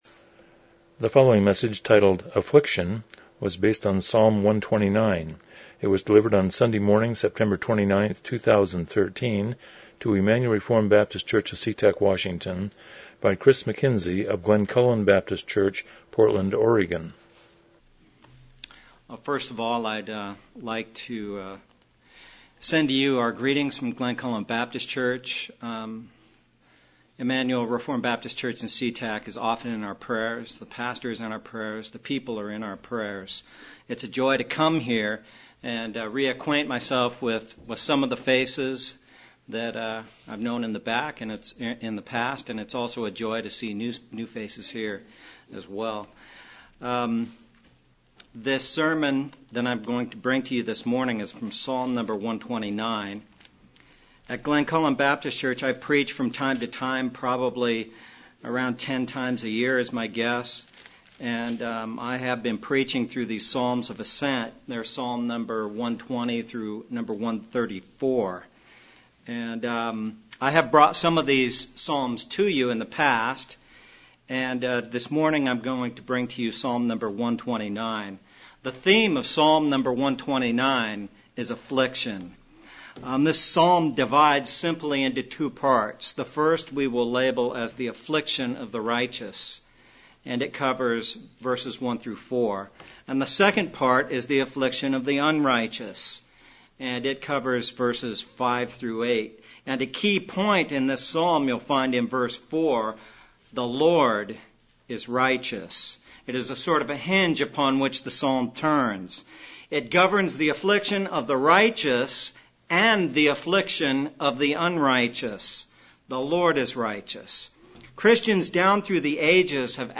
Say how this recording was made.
Miscellaneous Service Type: Morning Worship « 19 The Problem of Evil 6